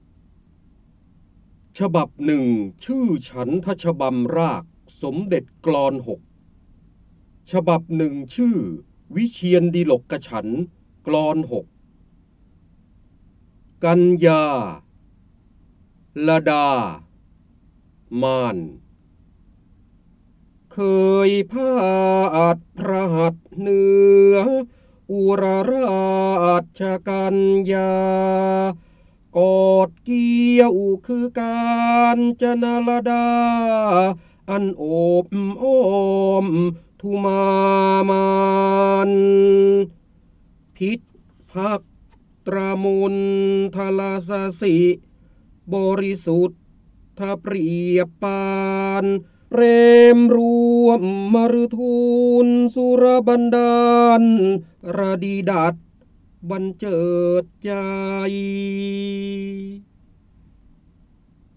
เสียงบรรยายจากหนังสือ จินดามณี (พระโหราธิบดี) ฉบับหนึ่งฉันทฉบำราคสมเด็จกลอน๖